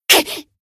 BA_V_Neru_Bunny_Battle_Damage_1.ogg